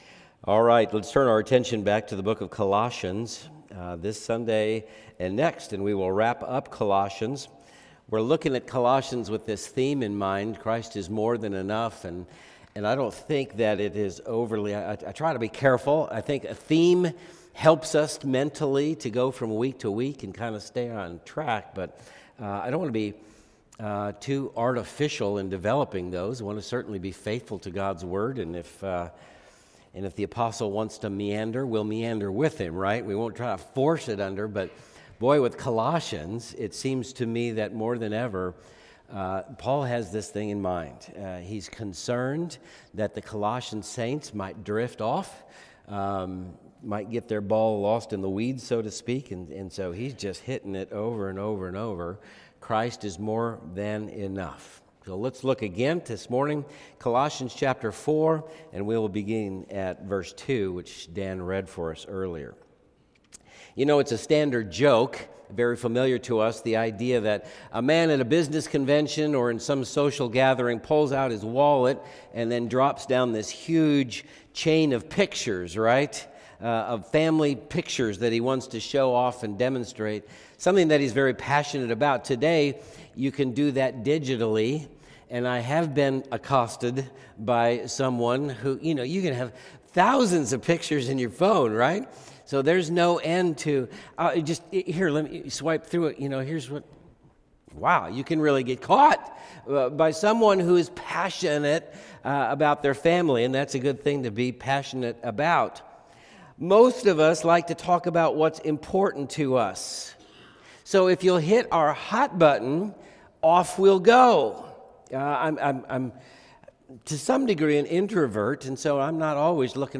Sermons | Converge Church